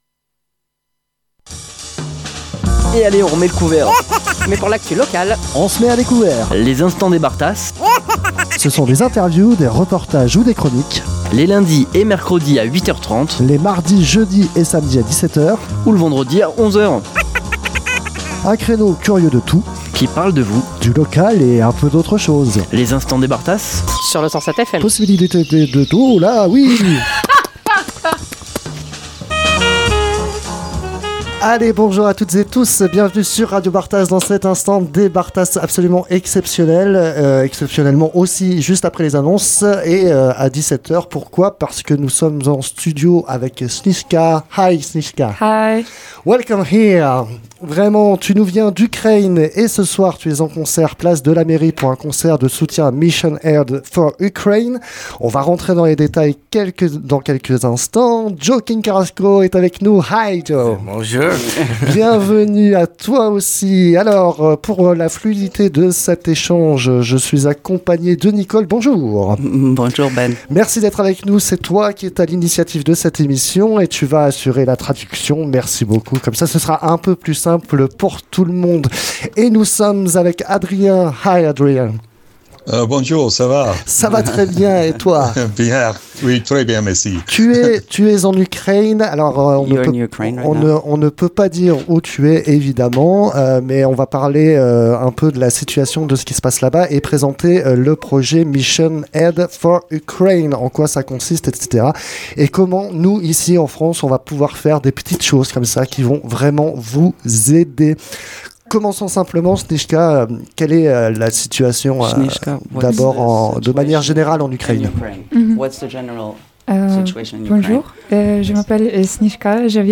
InterviewOkazia.mp3